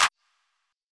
PBL Clap (1).wav